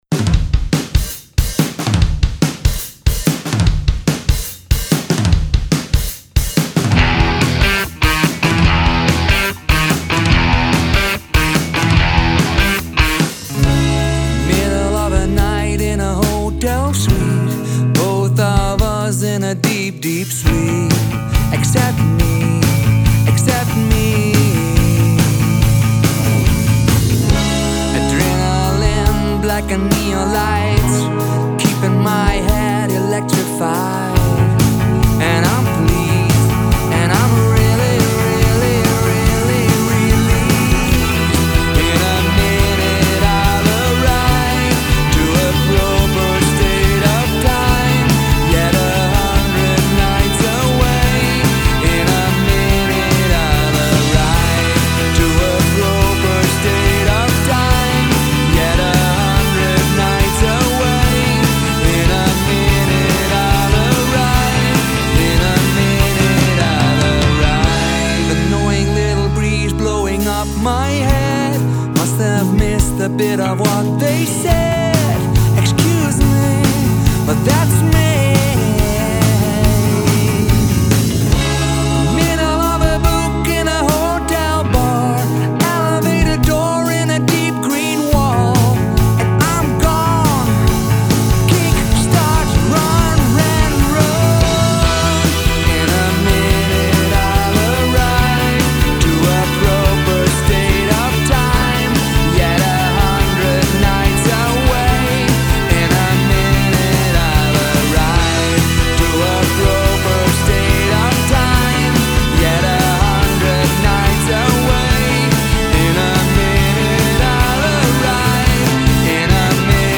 Catchy indietrack